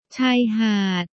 La Plage ชายหาด Chayhad